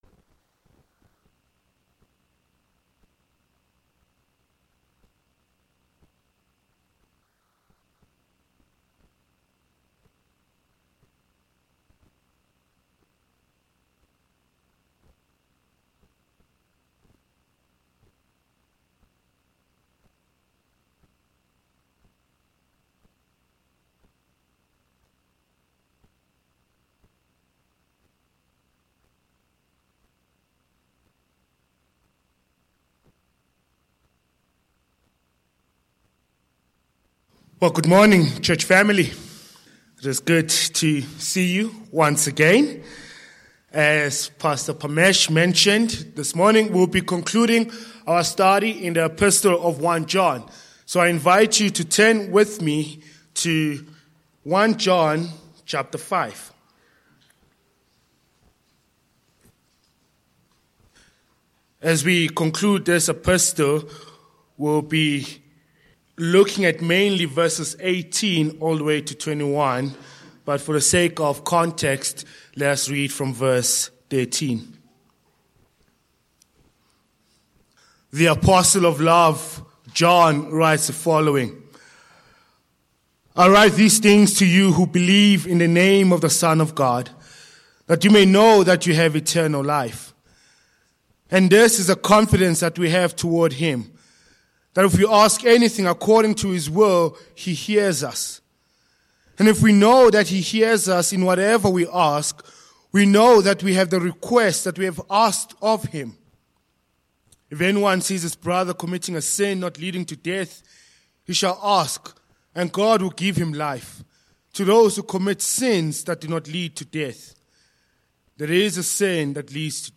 Sermon Points 1.
Service Type: Morning